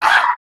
damage_1.wav